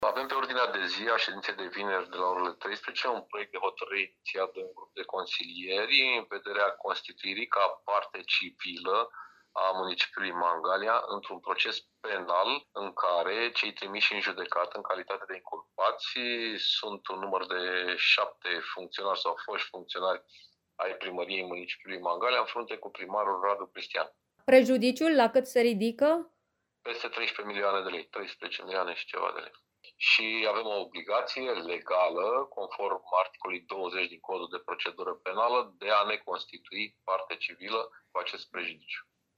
Despre ce dosar este vorba, ne explică viceprimarul municipiului Mangalia, Paul Foleanu, unul dintre inițiatorii singurului proiect de hotărâre aflat pe ordinea de zi.